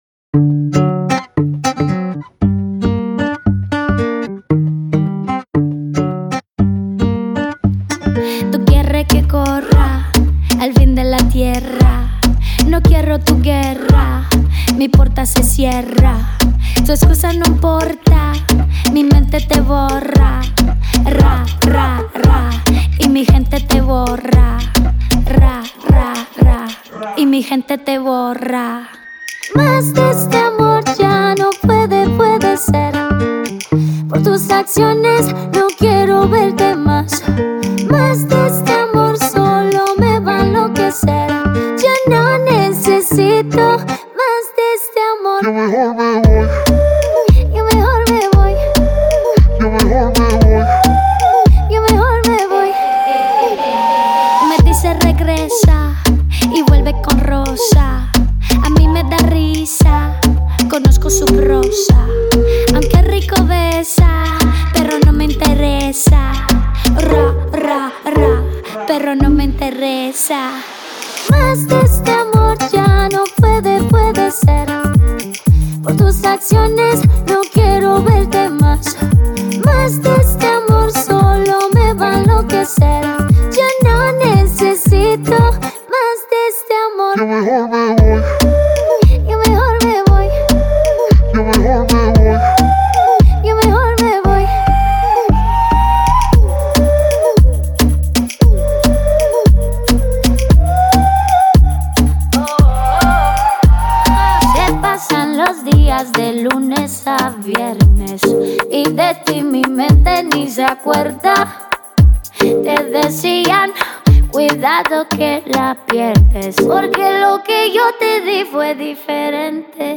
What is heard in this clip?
Genre: Punjabi